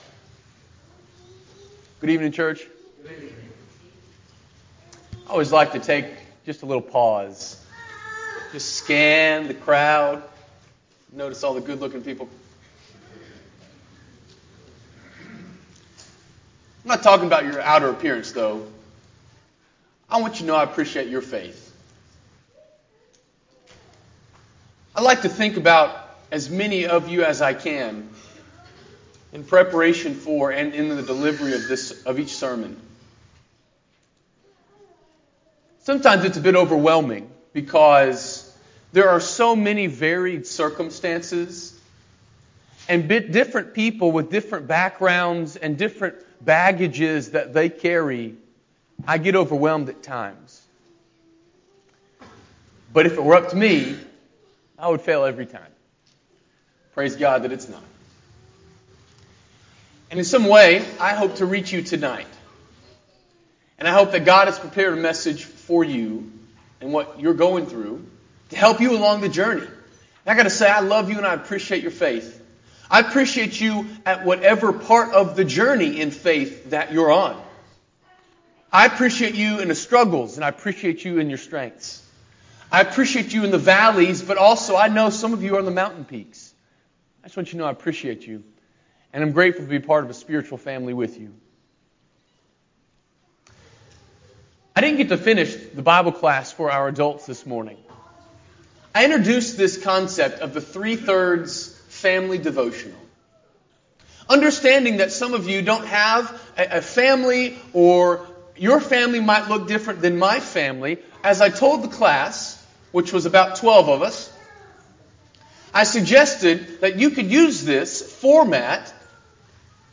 FAMILY DEVOTIONAL